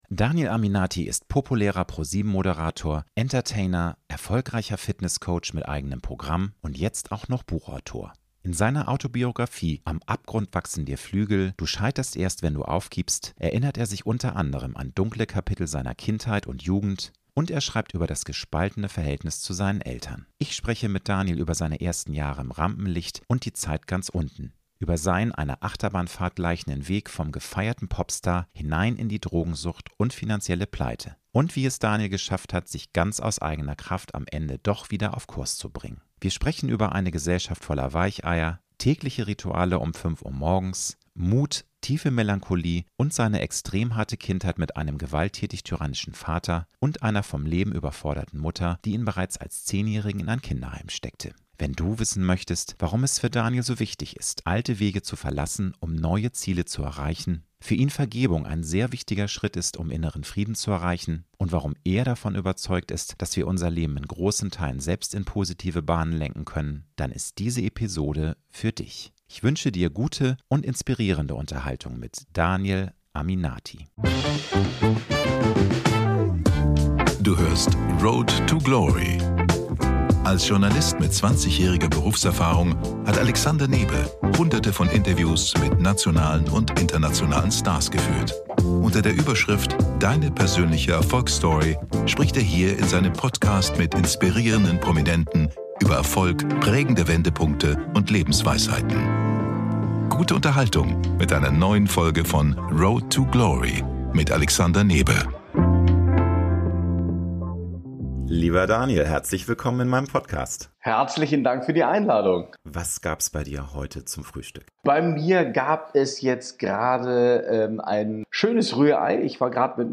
Ich wünsche dir gute und inspirierende Unterhaltung mit Daniel Aminati.